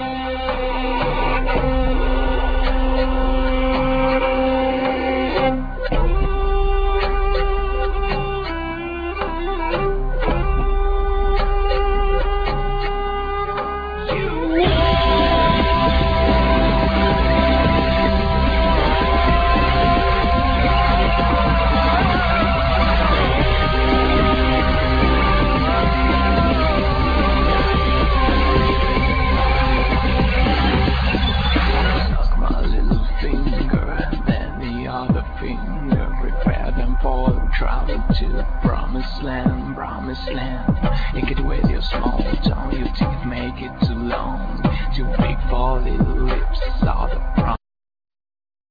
Drums,Djembe,Programming
Vocals,Keyboards,Programming
Violin
Djembe,Doundoun
Guitar